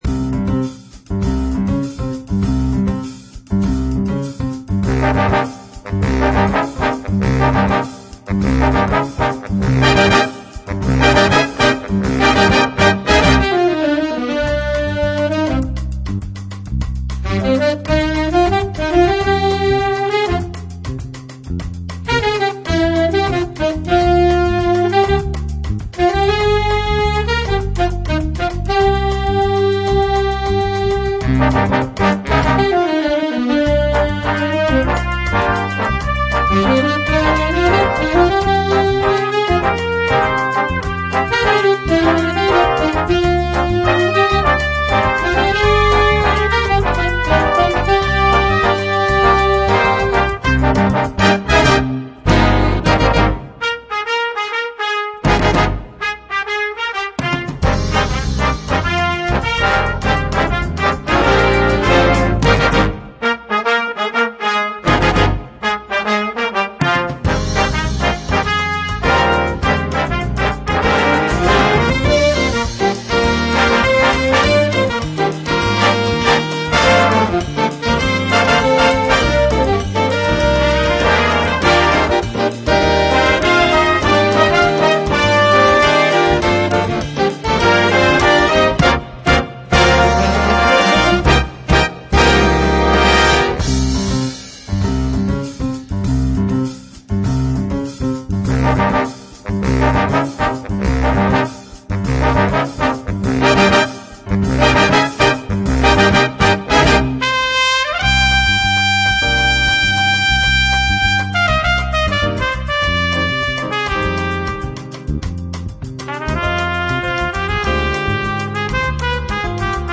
Grade: 4 Style: Samba Duration: 4’01"
Solo space for all.
Jazz Ensemble